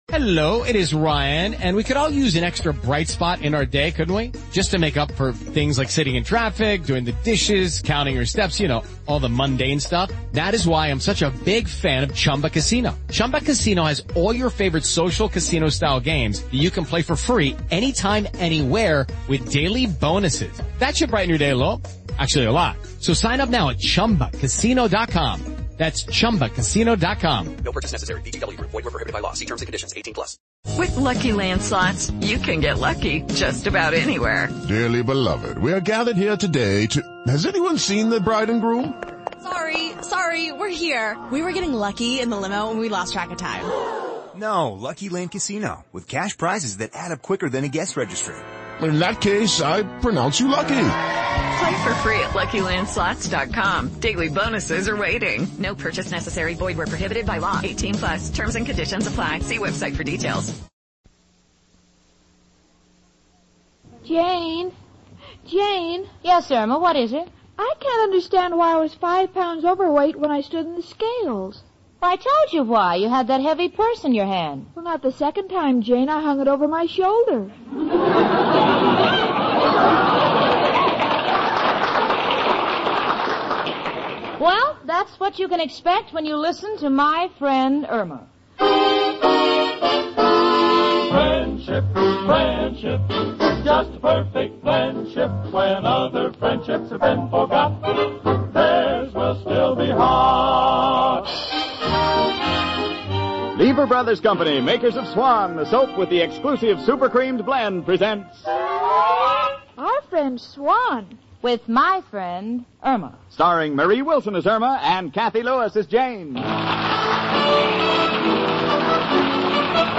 "My Friend Irma," the classic radio sitcom that had audiences cackling from 1946 to 1952!
Irma, played to perfection by the inimitable Marie Wilson, was the quintessential "dumb blonde."